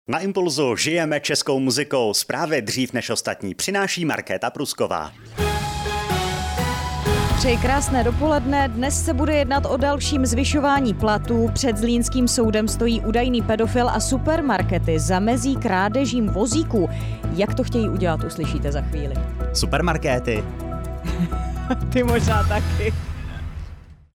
Rádio Impuls – teaser 🙂